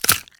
bone_break_neck_snap_crack_04.wav